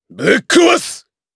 Chase-Vox_Skill1_jp.wav